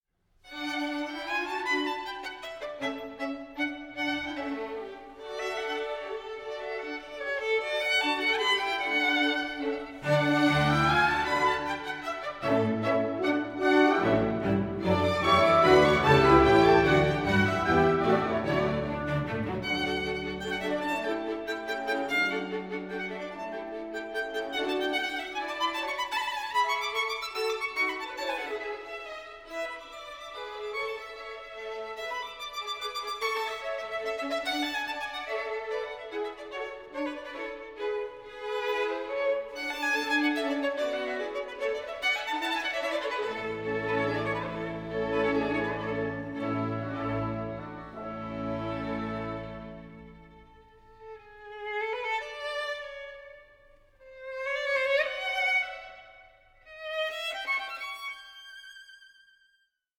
Concerto for Violin & Orchestra No. 2 in D Major
Allegro 4:20